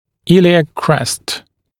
[‘ɪlɪæk krest][‘илиэк крэст]подвздошный гребень